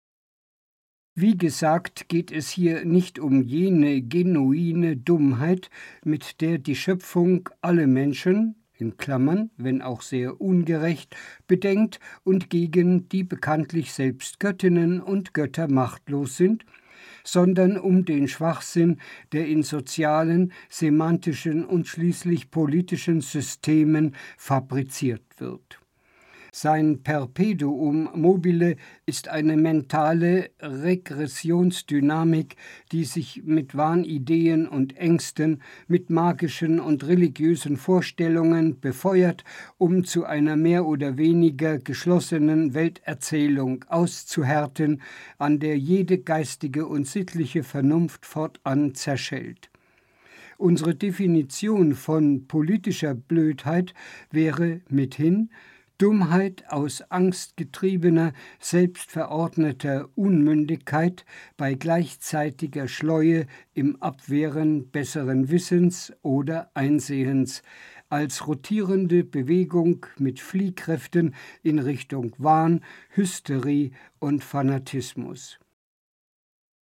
DAISY-Hörbuch